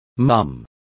Complete with pronunciation of the translation of mums.